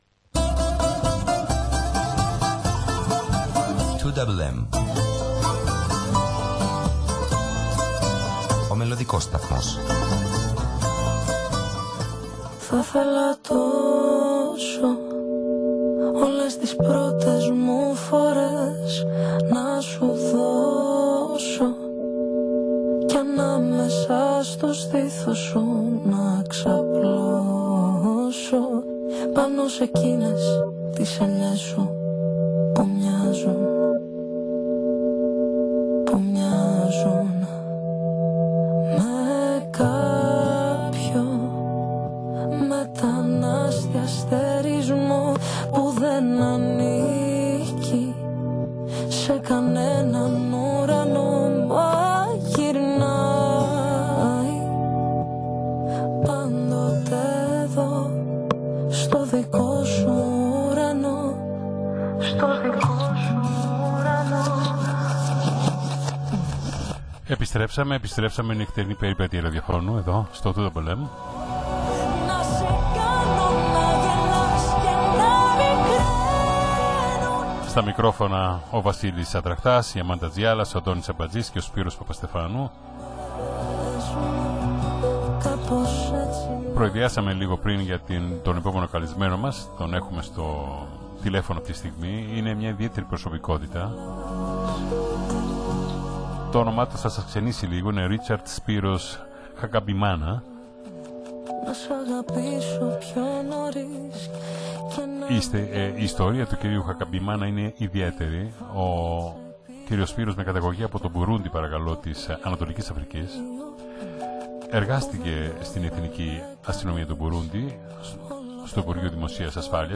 ζωντανή συνέντευξη